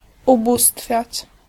Ääntäminen
Synonyymit dote Ääntäminen US Tuntematon aksentti: IPA : /əˈdɔː/ IPA : /əˈdɔːr/ Haettu sana löytyi näillä lähdekielillä: englanti Käännös Ääninäyte Verbit 1. wielbić 2. uwielbiać 3. ubóstwiać Määritelmät Verbit To worship .